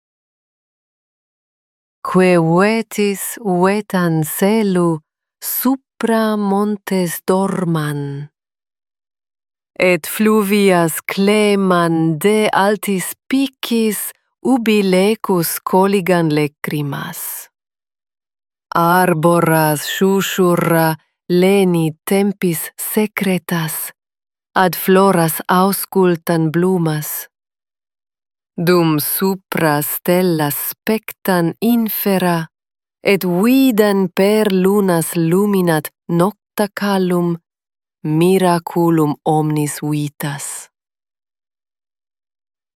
An  Elvish Poem — Recited in Elvish